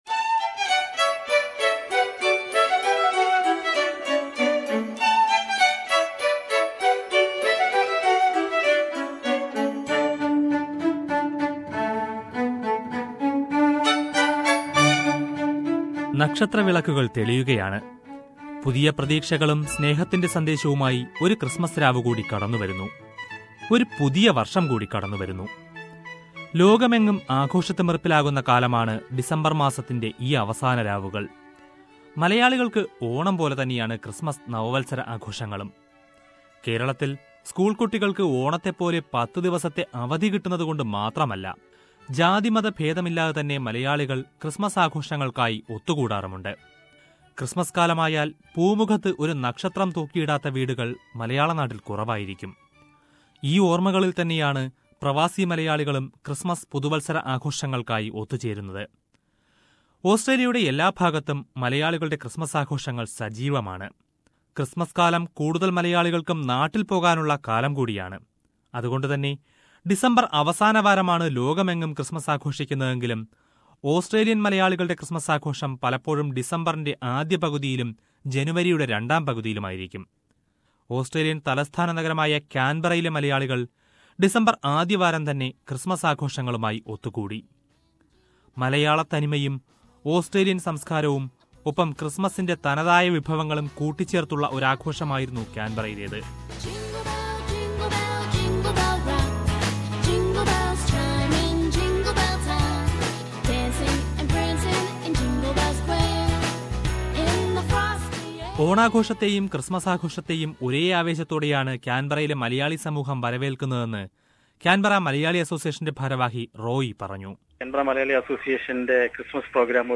Malayalees around Australia organise a number of Christmas and New Year functions. Listen to a report by SBS Malayalam Radio on the celebration by Malayalees across Australia..
From the Christmas celebration of Darwin Malayalee Association